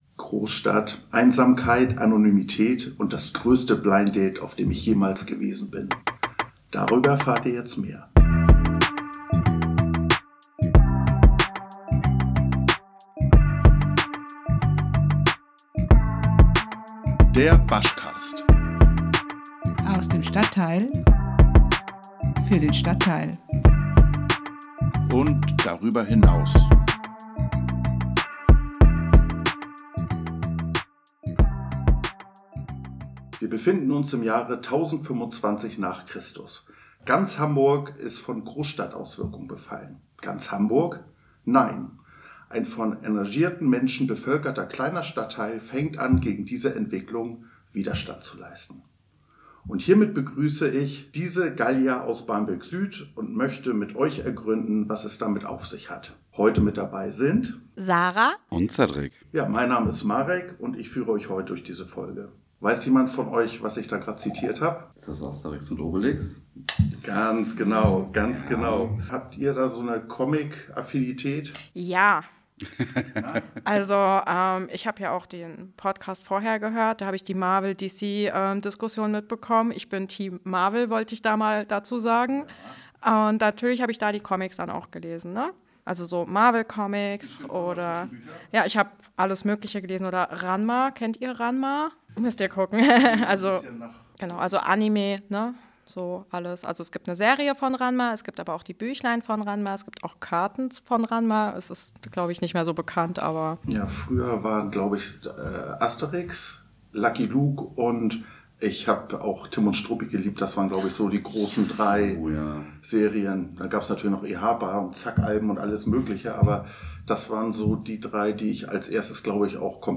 Das muss nicht sein, wie wir heute in unserem Gespräch erfahren. Was macht gute Nachbarschaft aus?